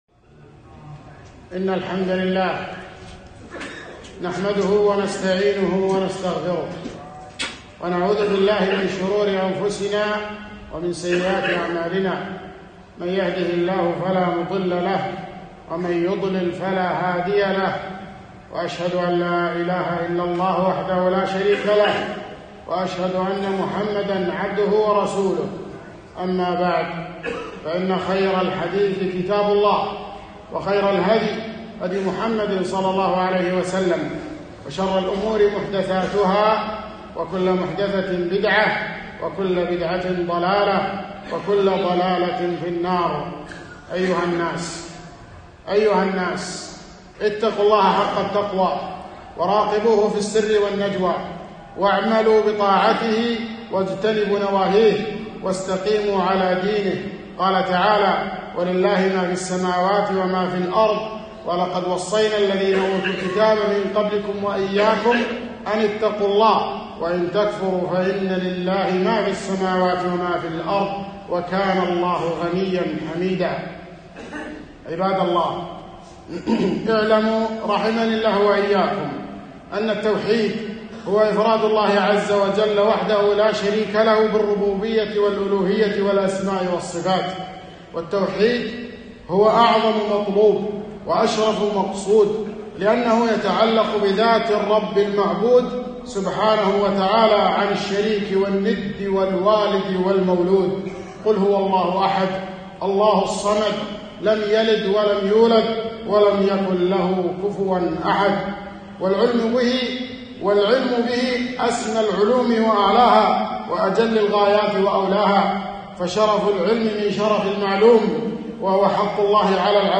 خطبة - التوحيد أهم ما في حياتك فتعلمه وعلمه أهلك